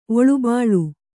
♪ oḷubāḷu